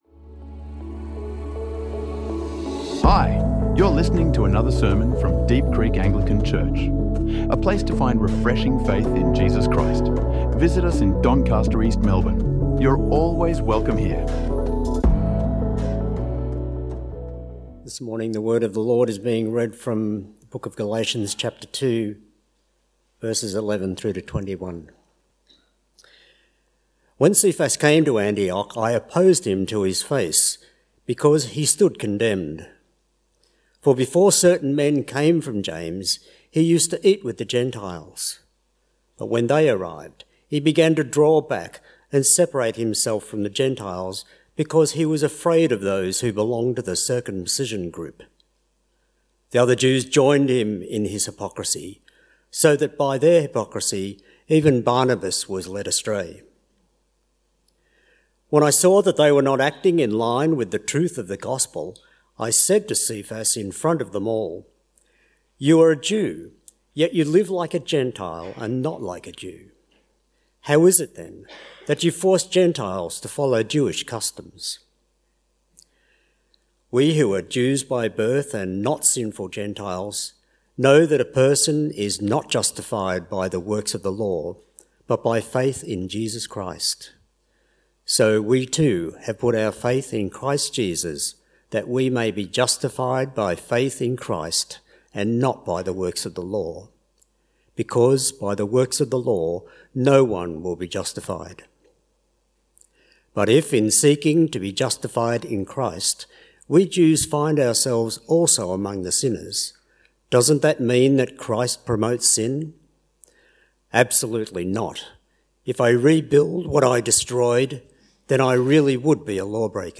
Seated at Freedom's Table | Sermons | Deep Creek Anglican Church